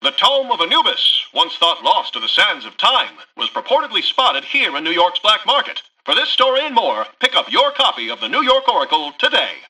Newscaster_headline_23.mp3